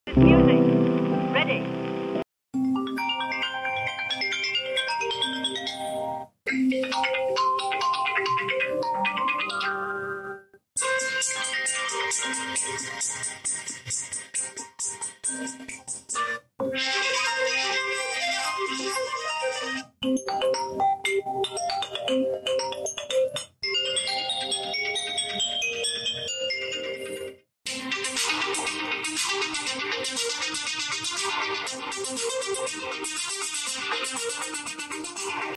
iOS 26 just dropped 7 🔥 new ringtones – and they’re actually GOOD!🎶